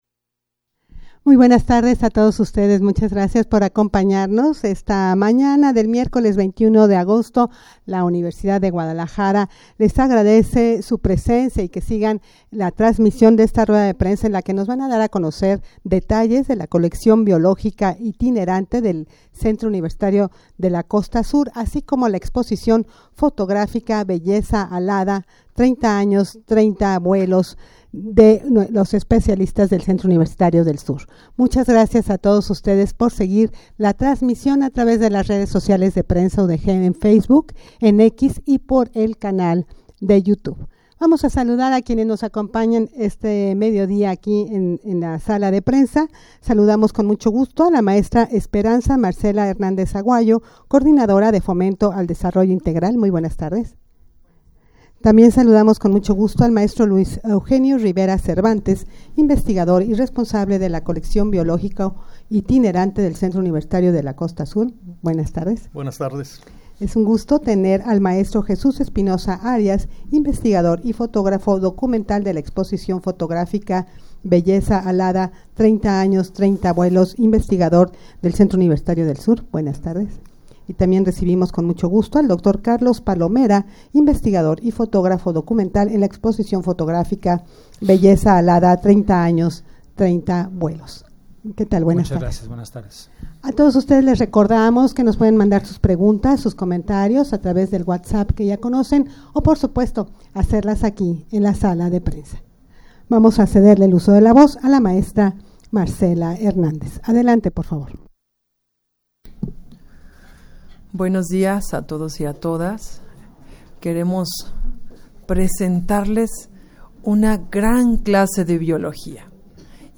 Audio de la Rueda de Prensa
rueda-de-prensa-para-dar-a-conocer-la-coleccion-biologica-itinerante-del-cucsur-.mp3